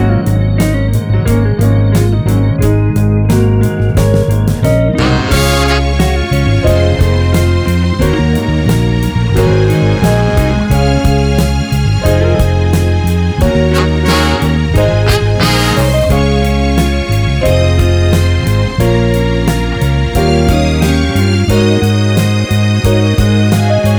No Backing Vocals Soul / Motown 4:31 Buy £1.50